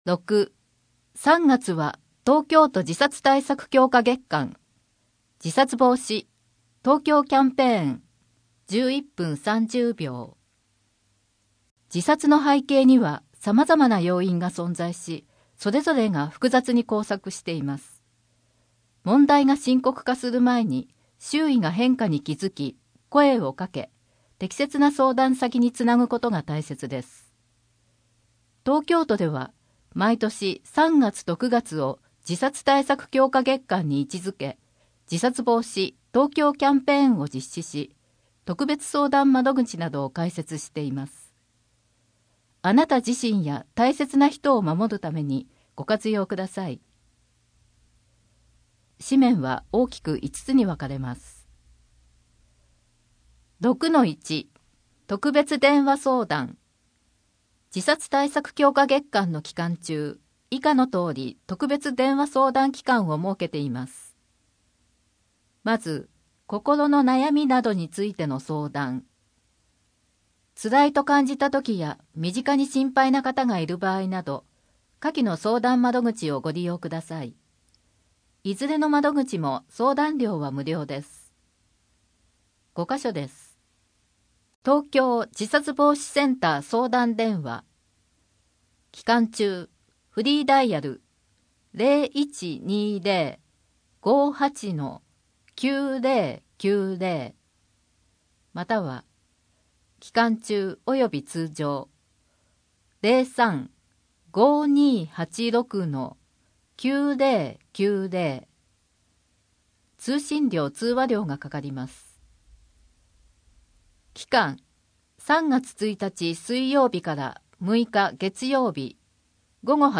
3月1日（水曜日）～7日（火曜日）は子ども予防接種週間 3月の子育て関連事業 3月の乳幼児健康診査 3月の休日診療 24時間電話で聞ける医療機関案内 平日小児準夜間診療 市報きよせ令和5年3月1日号 8面 （PDF 546.3KB） 声の広報 声の広報は清瀬市公共刊行物音訳機関が制作しています。